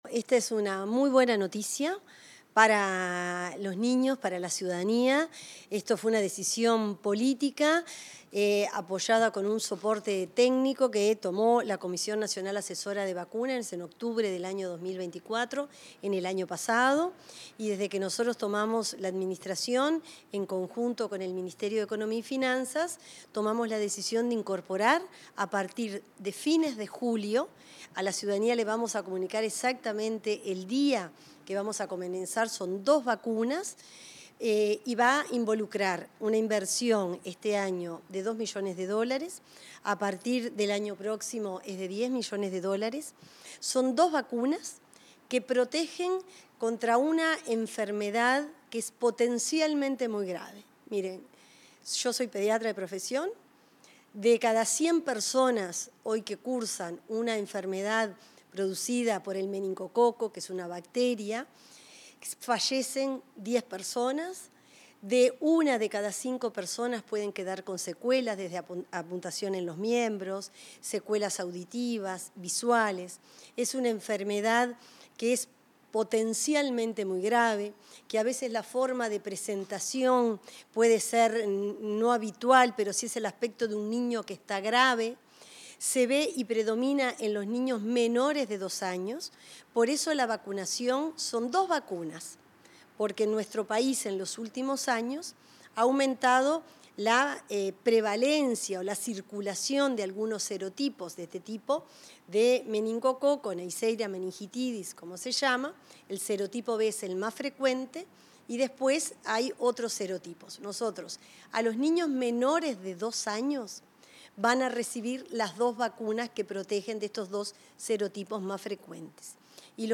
Palabras de la ministra de Salud Pública, Cristina Lustemberg